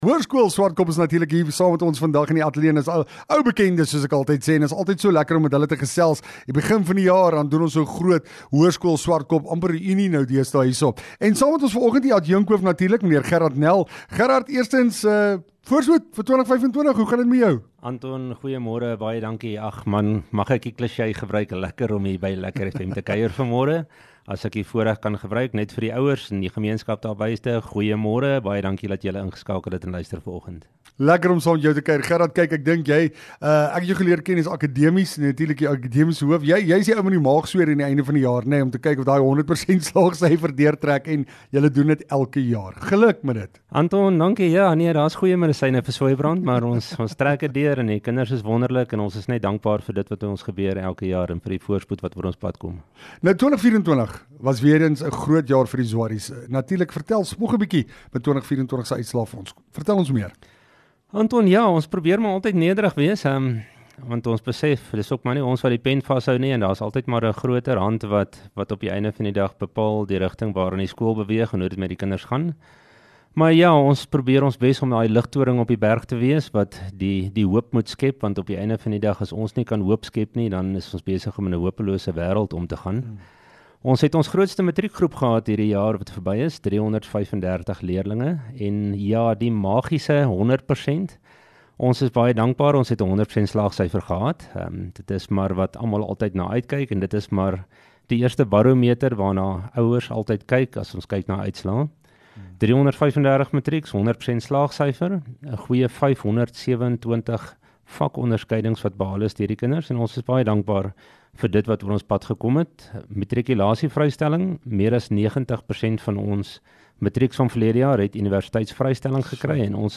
Hoërskool Zwartkop Onderhoud 21-01-25